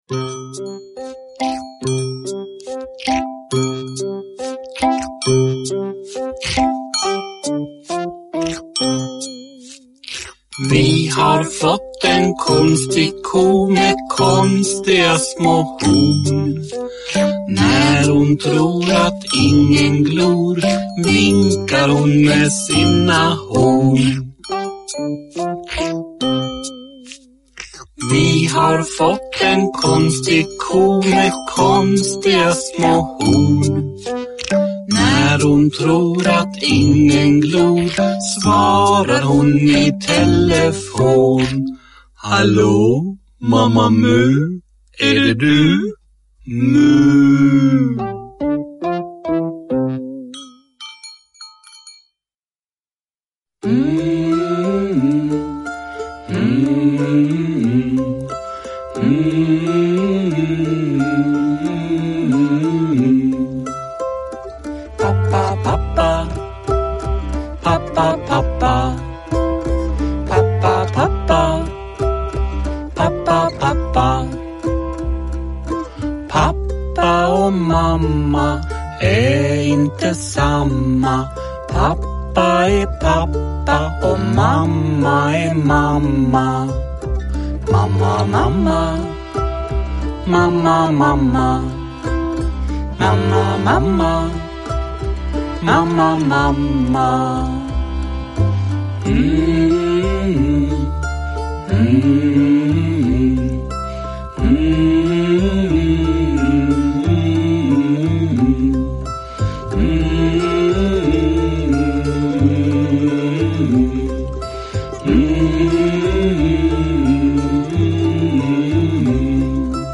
Uppläsning med musik.
Uppläsare: Jujja Wieslander